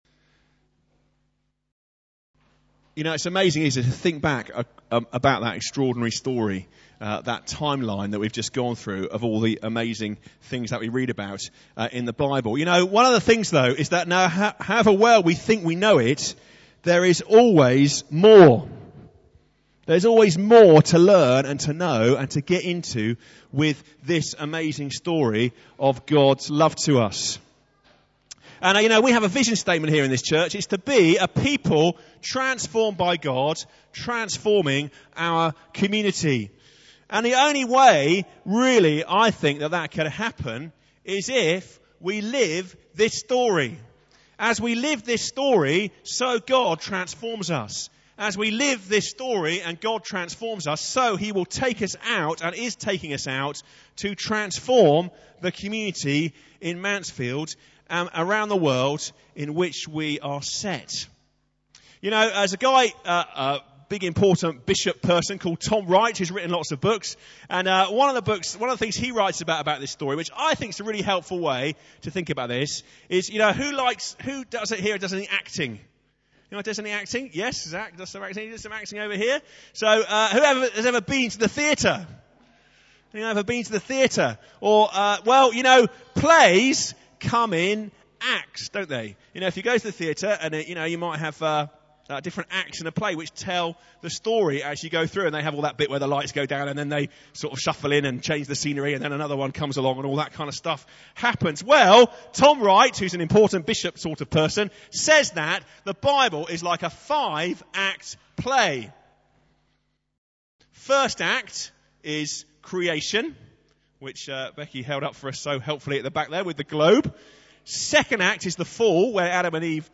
10:30 Morning Worship, St John's service